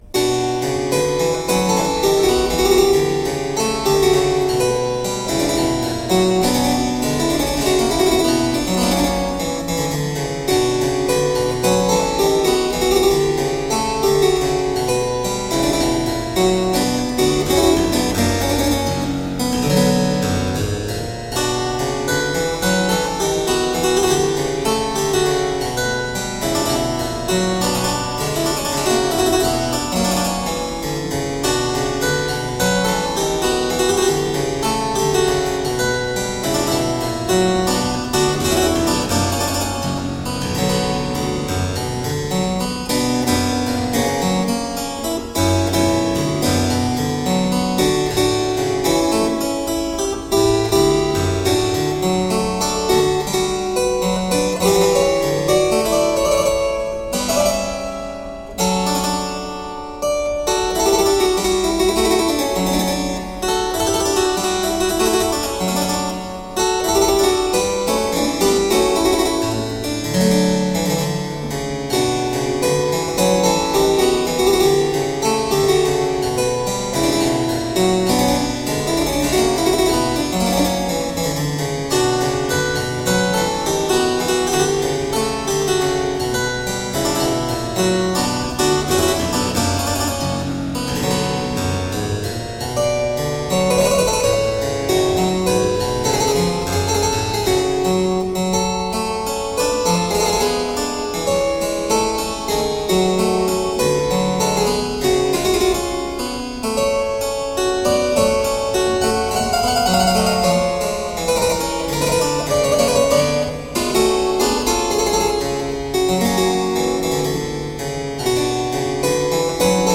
Crisp, dynamic harpsichord.